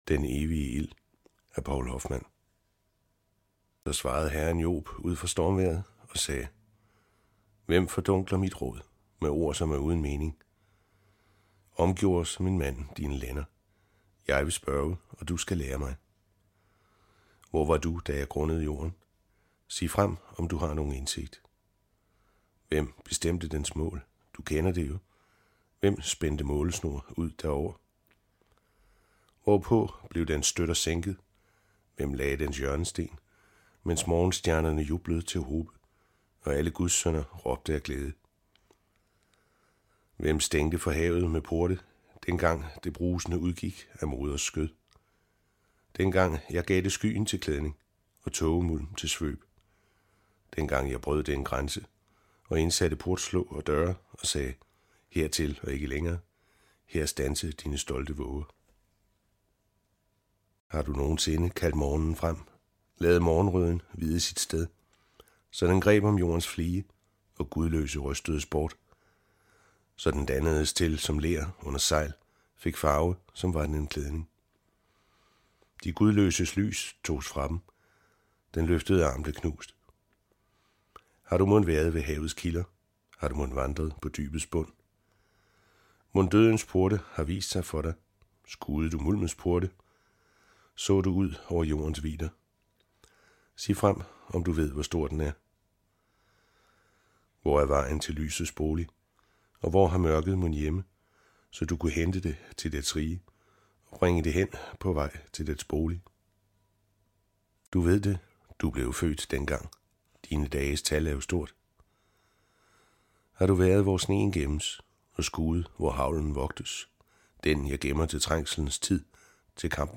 Hør et uddrag af Den evige ild Den evige ild Moses II Format MP3 Forfatter Poul Hoffmann Lydbog E-bog 149,95 kr.